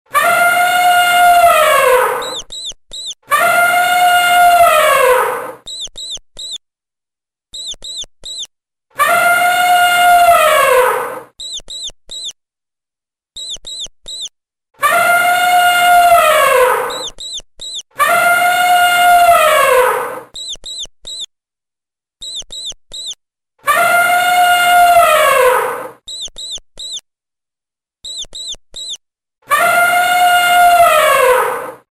시차를 두어 코끼리 울음소리 사이에 쥐소리를 들려주면 됩니다.
<들어보기 2> 코끼리 소리와 쥐 소리를 시차를 두고 녹음한 파일
04 코끼리 쥐 따로 30초.mp3